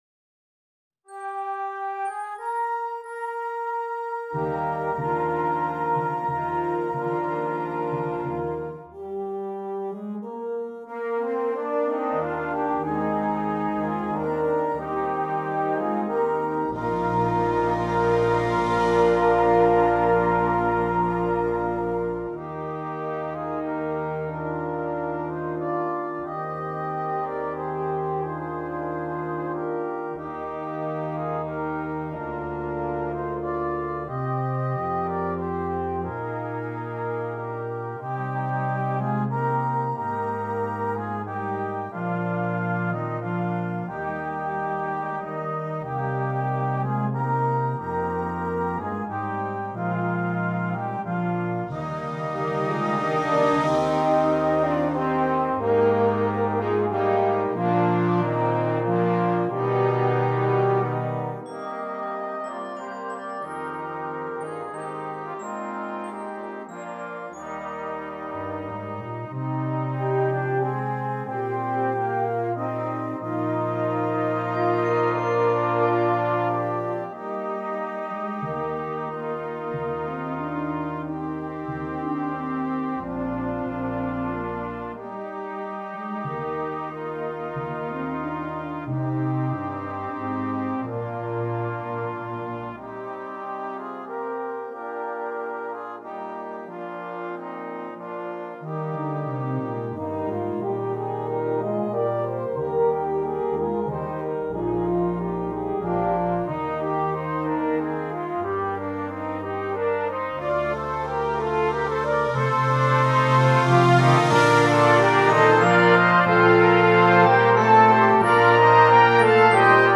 Brass Choir
Traditional Spanish Carol